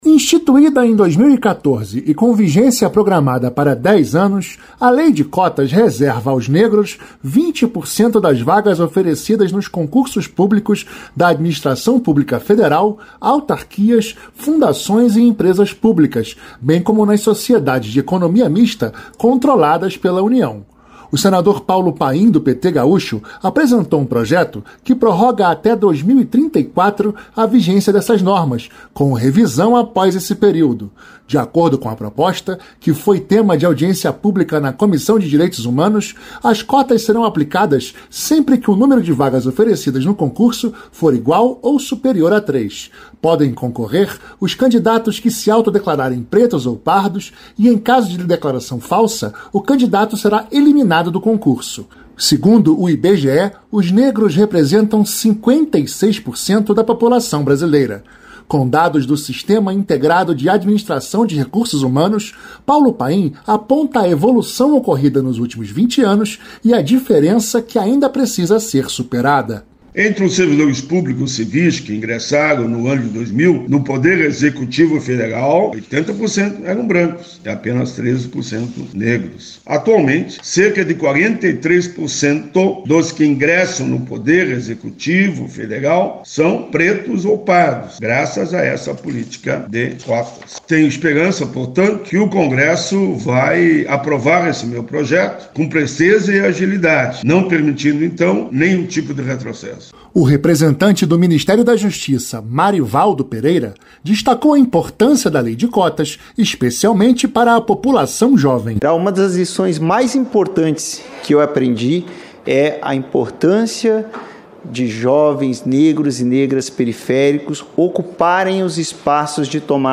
O senador Paulo Paim (PT-RS) apresentou um projeto (PL 1958/2021) que prorroga até 2034 a vigência dessas normas, com revisão após esse período. De acordo com a proposta, que foi tema de audiência pública na Comissão de Direitos Humanos nesta quarta-feira (22), as cotas serão aplicadas sempre que o número de vagas oferecidas no concurso público for igual ou superior a três.